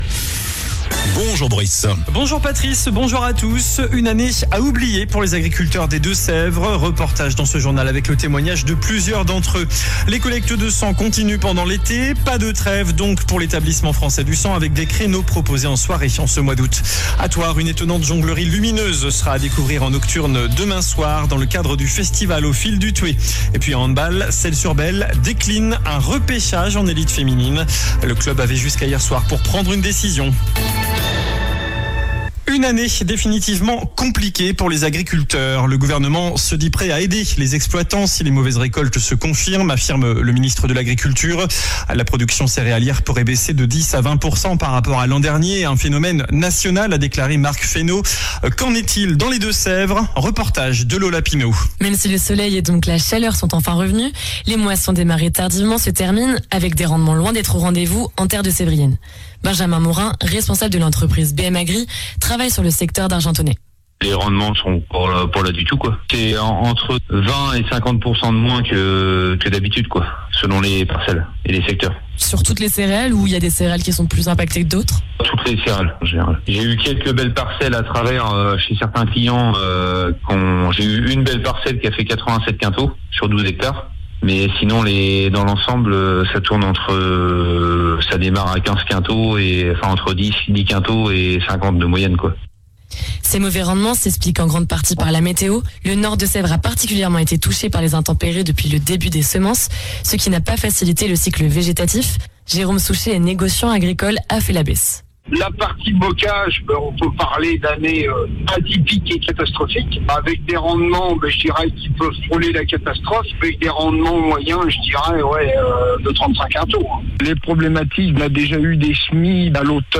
JOURNAL DU MARDI 06 AOÛT ( MIDI )
Une année à oublier pour les agriculteurs des Deux-Sèvres. Le témoignage de plusieurs d’entre eux dans le journal..